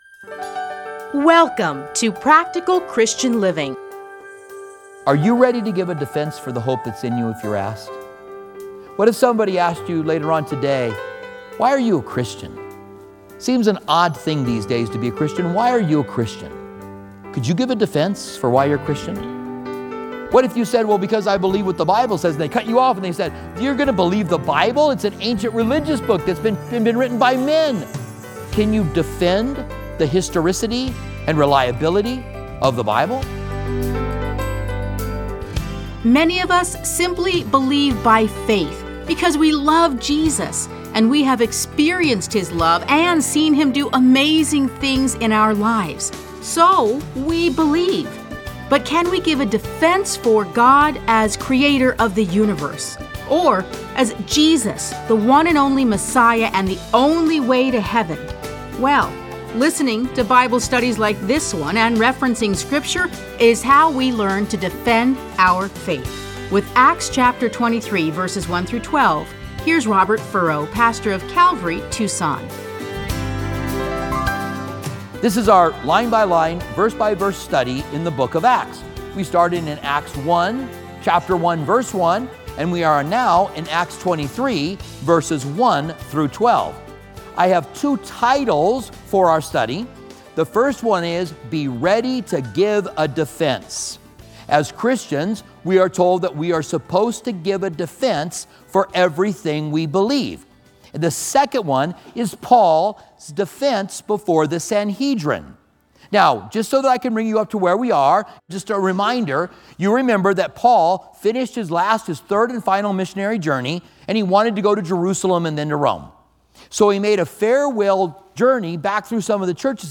Listen to a teaching from Acts 23:1-12.